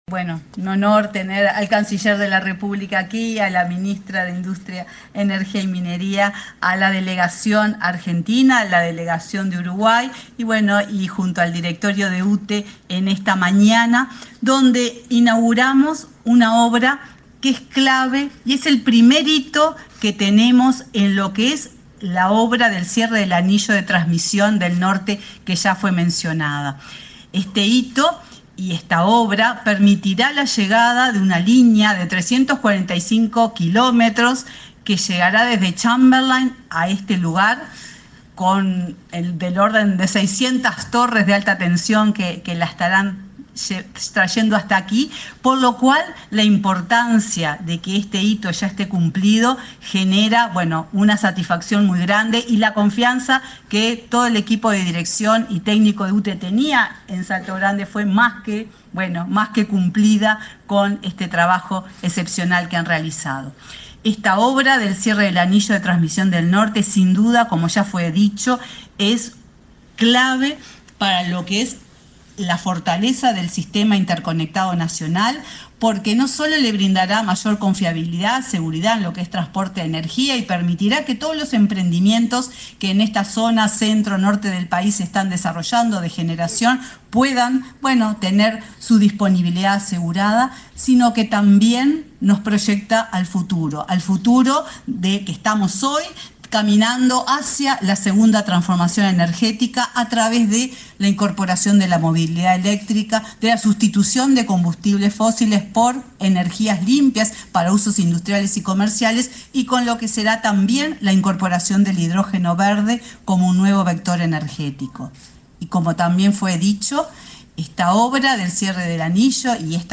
Palabras de autoridades en acto en Salto Grande
Palabras de autoridades en acto en Salto Grande 14/03/2024 Compartir Facebook X Copiar enlace WhatsApp LinkedIn La presidenta de la UTE, Silvia Emaldi, el canciller Omar Paganini y la ministra de Industria, Elisa Facio, participaron, este jueves 14, en la inauguración de la subestación Salto Grande, concretada en el marco de un convenio entre el referido organismo y la Comisión Técnica Mixta de Salto Grande.